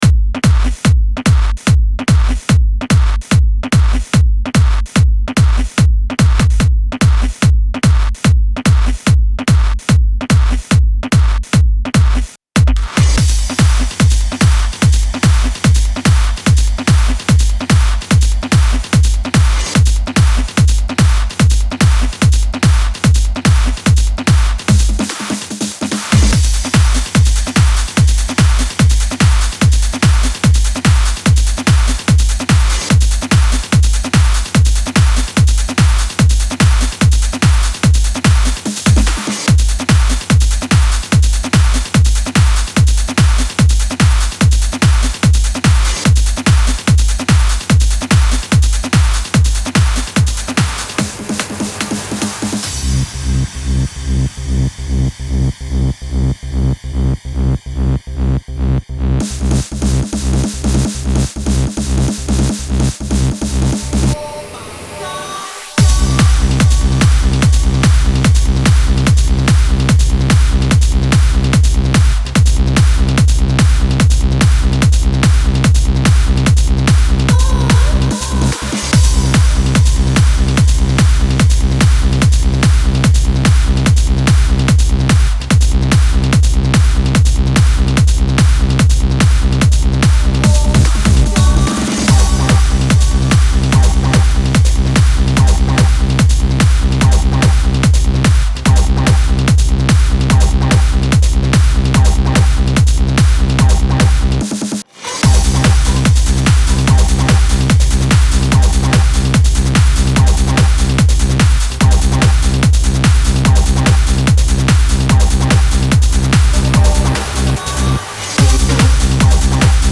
Free hard trance, hard dance music downloads